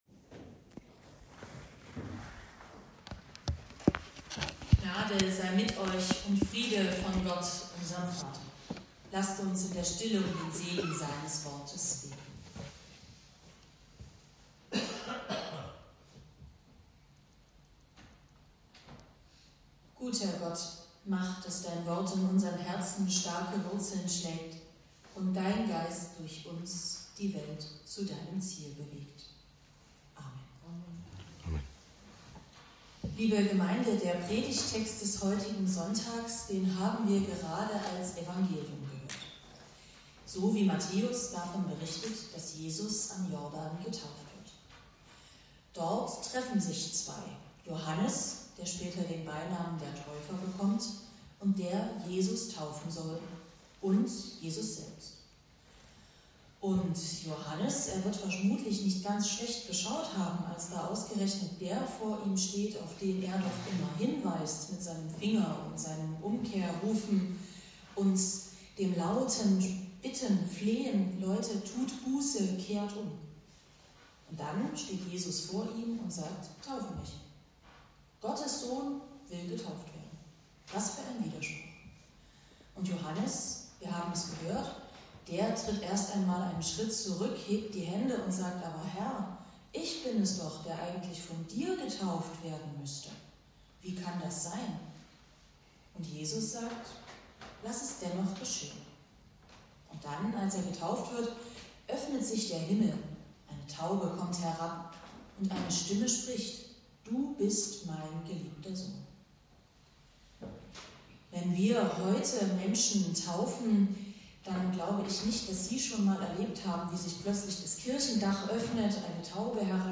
Matth. 3;13-17 Gottesdienstart: Predigtgottesdienst Wildenau Die Taufe macht die Tür zu Gottes Ewigkeit auf.